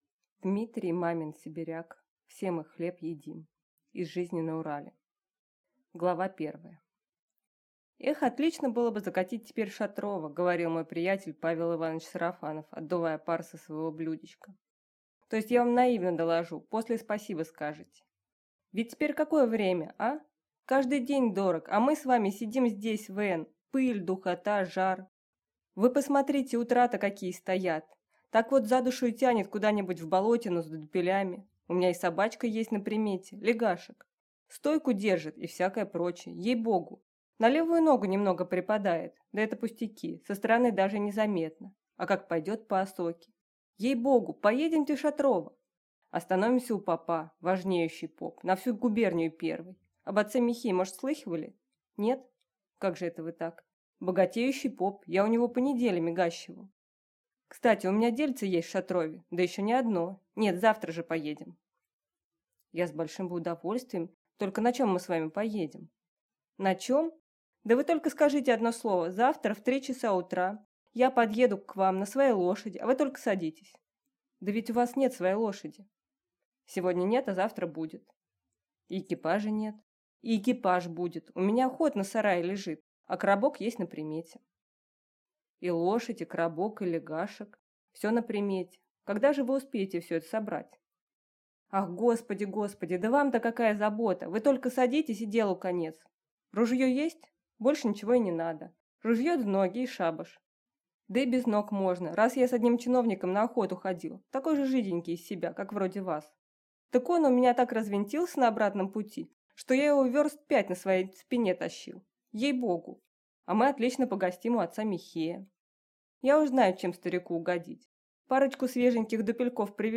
Аудиокнига «Все мы хлеб едим…» Из жизни на Урале | Библиотека аудиокниг